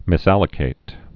(mĭs-ălə-kāt)